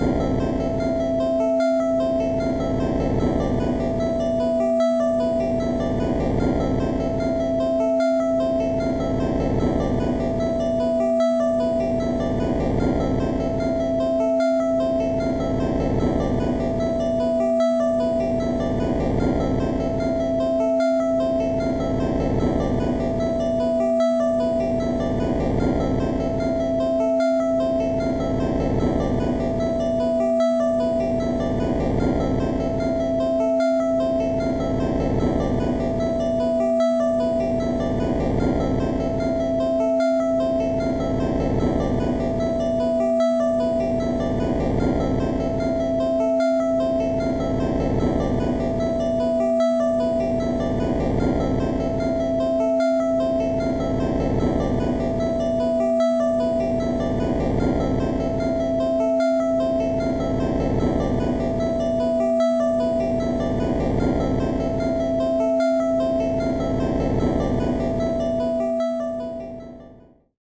Speed 150%